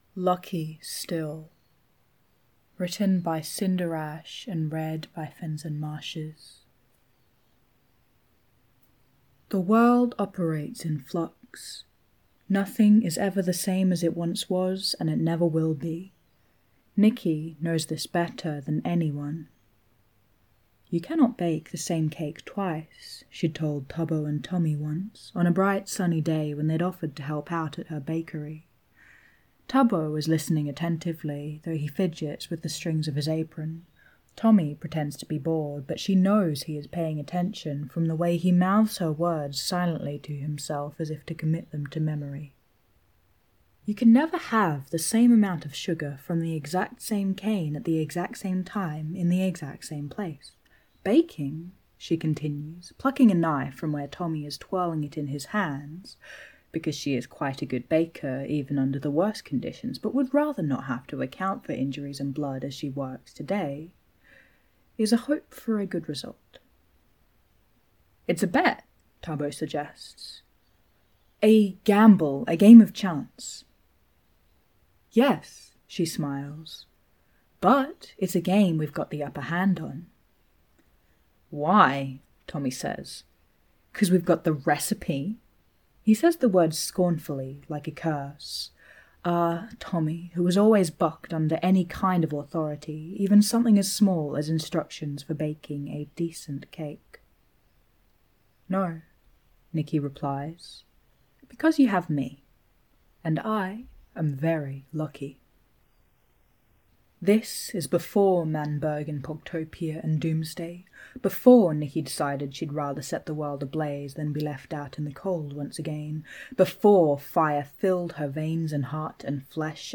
with music